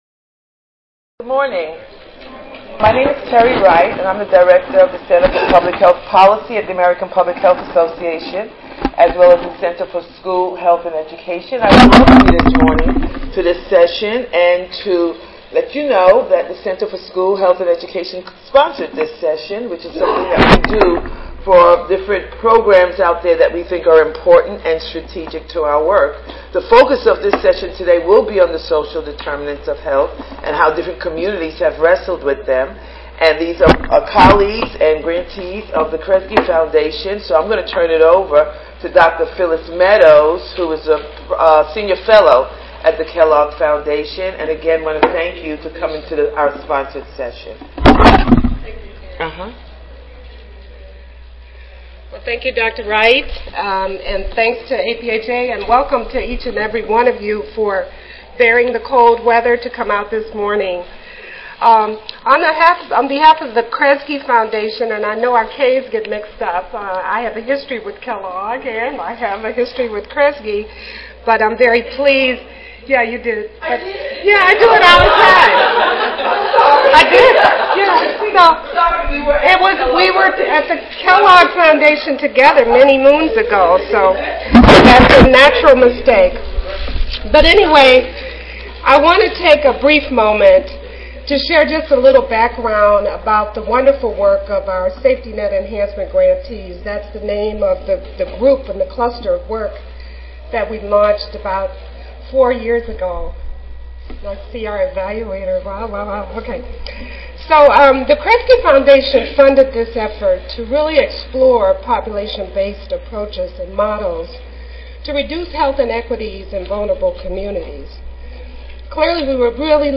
Annual Meeting Recordings are now available for purchase 4009.0 Strengthening Community Connectivity: Key Strategies to Reduce Health Inequities Tuesday, November 18, 2014: 8:30 AM - 10:00 AM Oral The efforts of several demonstration models and the population health approaches used to address social and environmental inequities within select communities will be presented.